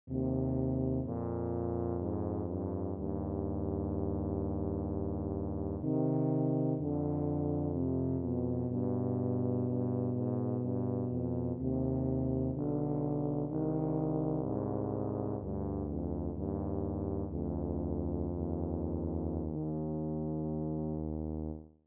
tuba duet